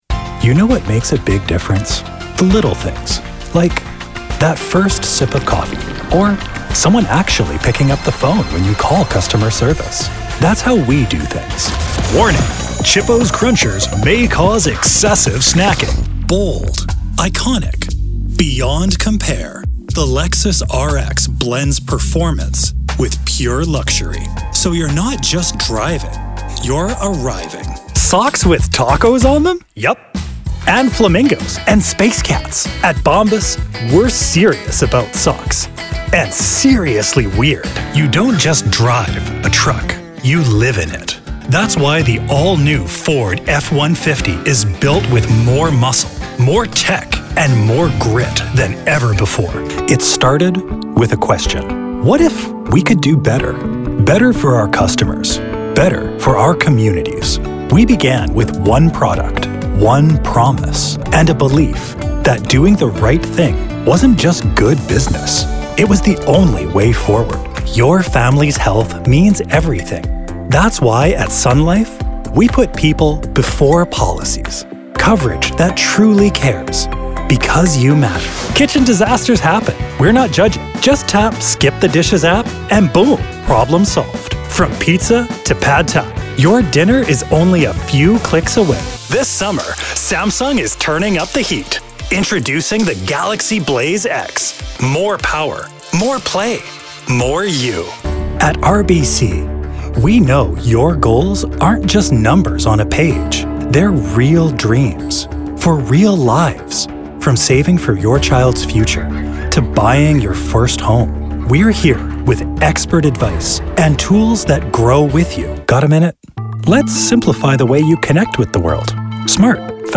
ACX Voice Over Commercial Actor + Voice Over Jobs
I can do conversational educational high energy nerd character robotic humanoid monster villain valley girl animated girl-next-door powerful serious sultry Italian Southern British professional storyteller expl...
Sophisticated, rich, trusting and confident, yet sensual and a true romantic at heart, Casting DIrectors, Directors, and Producers, say he has a "unique " timbre or voice quality that offers a smooth delivery f...
1118Commercial_Demo_Ext.mp3